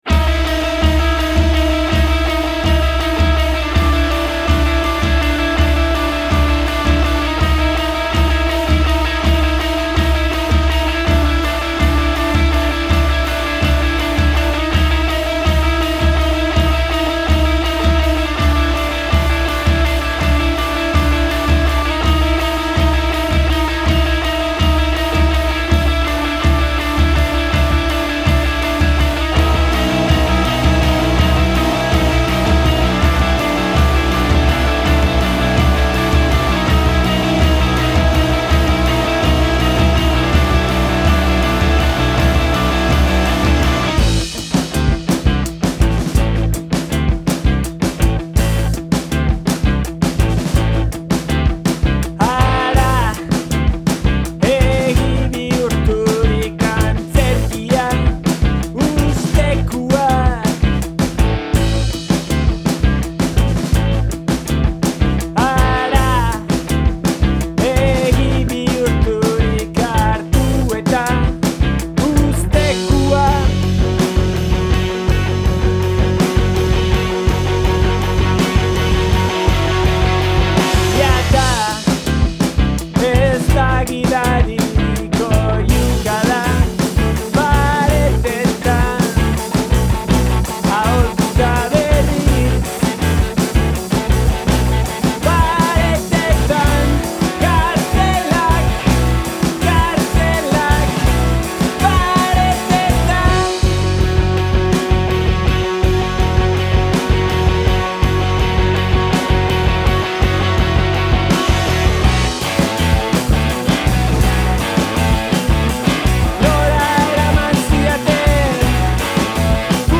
saxofoiaren erabilerak nabarmenago egiten dituenak apika.